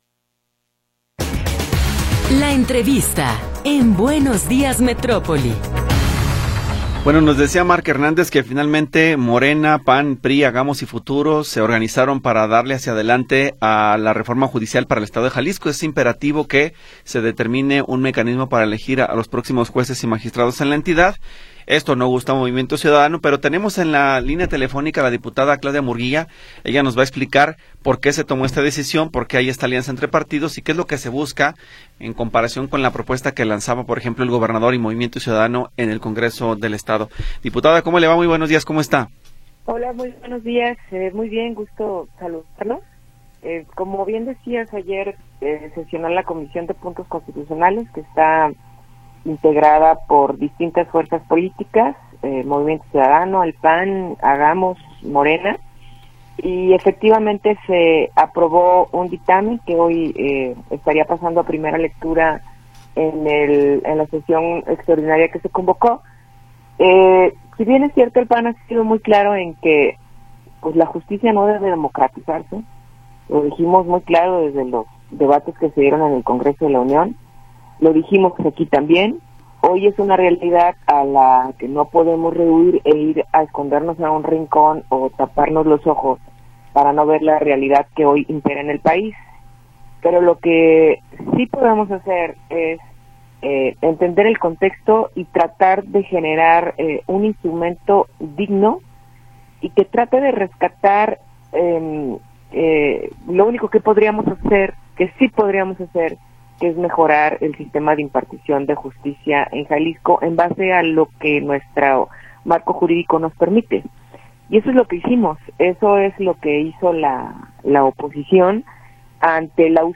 Entrevista con Claudia Murguía Torres